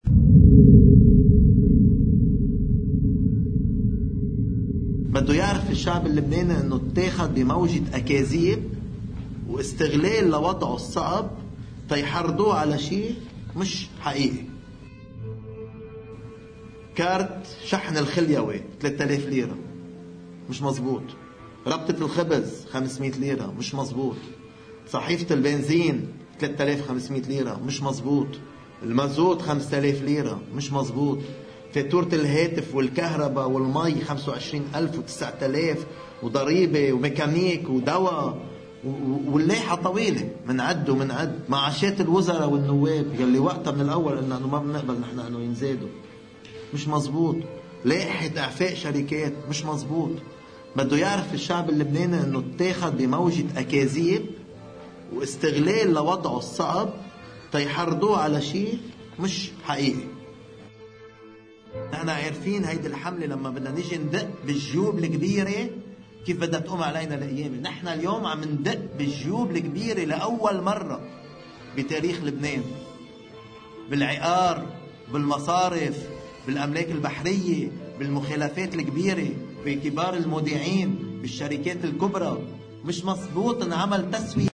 مقتطف من حديث رئيس التيار الوطني الحرّ جبران باسيل في مؤتمره الصحفي الأخير: